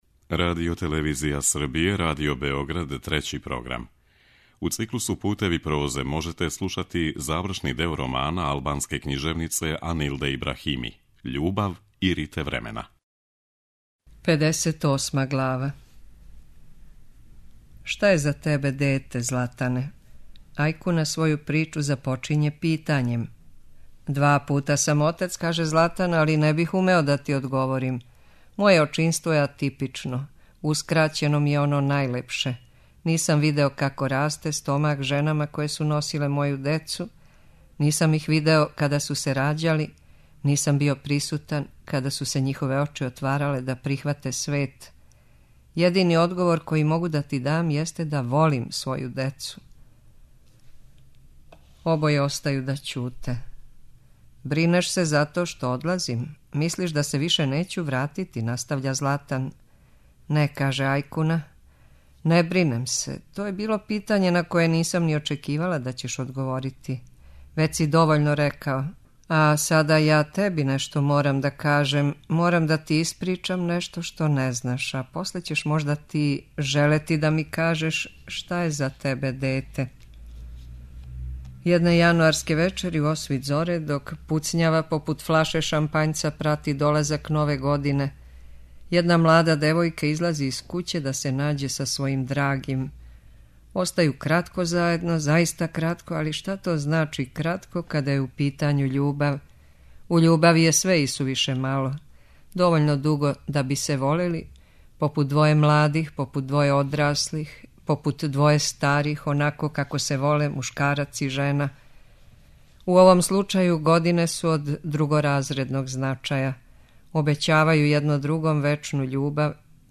У вечерашњој емисији прочитаћемо завршни одломак романа.
преузми : 12.60 MB Књига за слушање Autor: Трећи програм Циклус „Књига за слушање” на програму је сваког дана, од 23.45 сати.